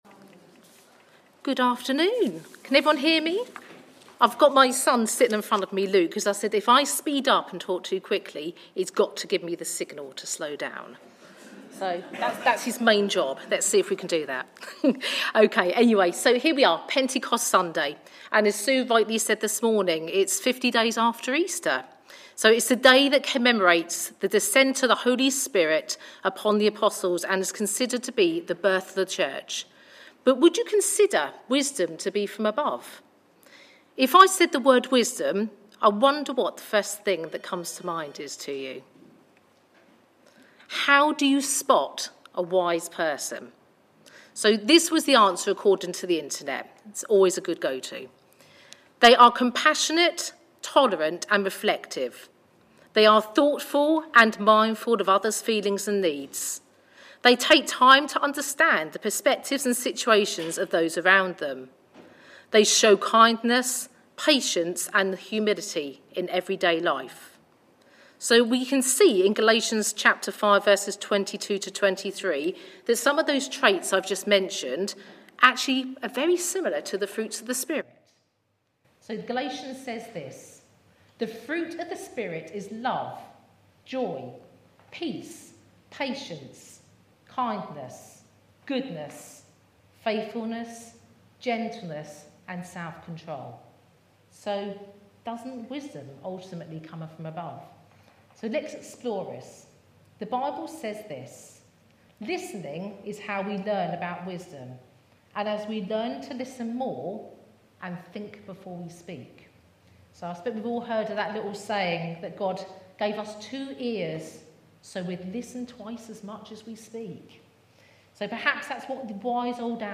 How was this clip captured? Messages from our Traditional Services